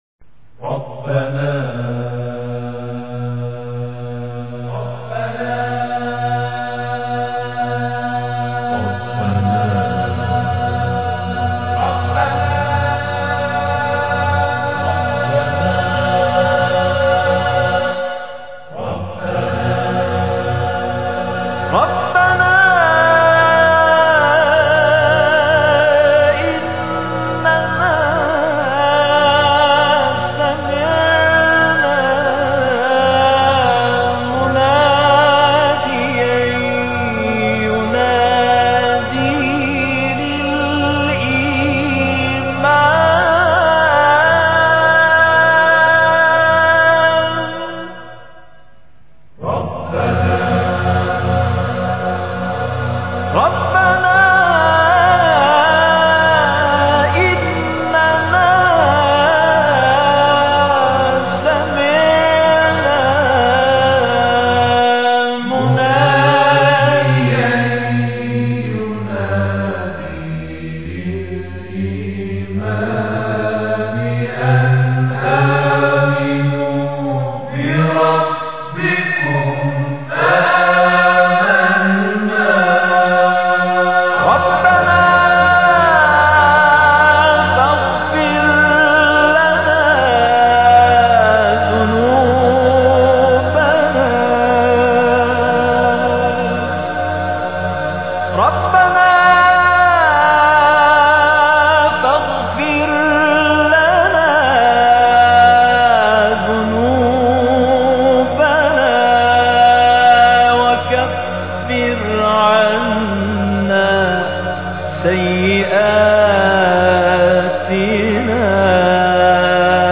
موزیک موزیک مذهبی